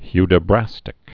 (hydə-brăstĭk)